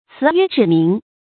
詞約指明 注音： ㄘㄧˊ ㄩㄝ ㄓㄧˇ ㄇㄧㄥˊ 讀音讀法： 意思解釋： 言詞簡潔，旨意明確。